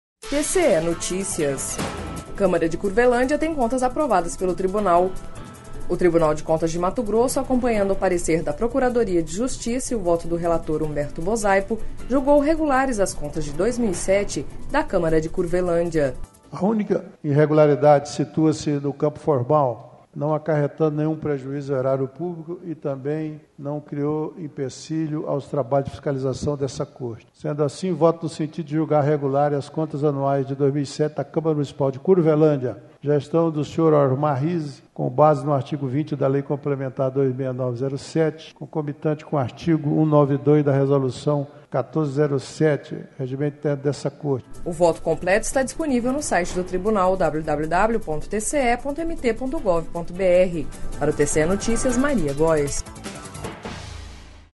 Sonora: Humberto Bosaipo – conselheiro TCE-MT